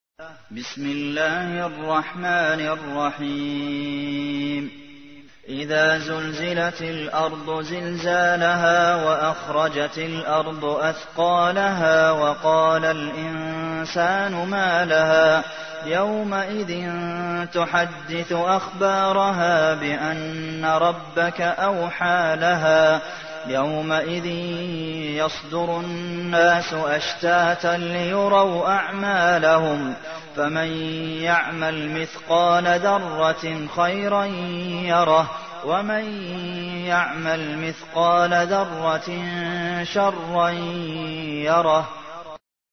تحميل : 99. سورة الزلزلة / القارئ عبد المحسن قاسم / القرآن الكريم / موقع يا حسين